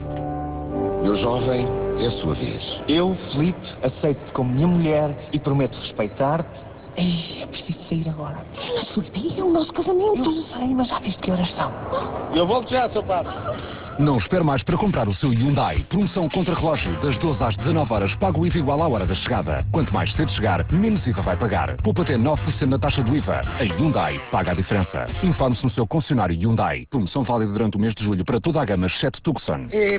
Estreou dia 13 de Julho uma campanha da marca Hyundai em que os concessionários oferecem parte do valor do IVA durante o mês de Julho, na compra de qualquer modelo da marca. Esta campanha passa na RR, RFM, RCP e RC. Desde a estreia do spot (